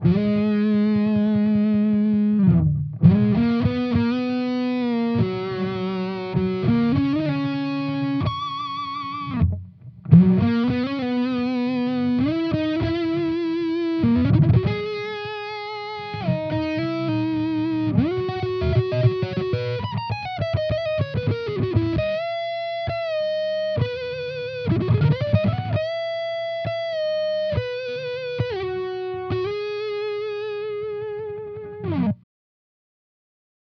Here are a few quick demos of my custom overdrive/distortion pedals for your listening pleasure.
Purple pedal with EQ
I used a Sennheiser MD441-U microphone on-axis, edge of speaker cap, and about an inch away from the grill.
purple-pedal-with-eq.wav